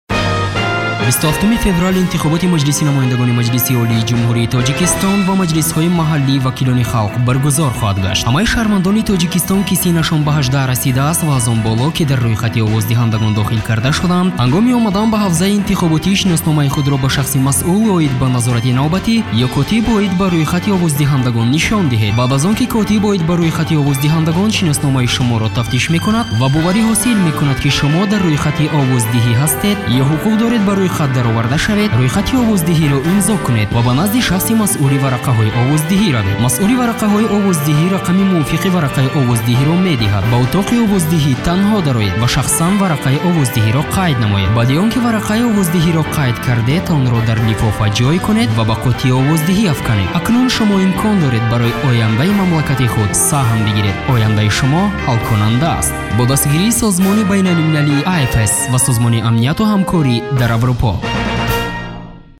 A thirty-second voter education radio message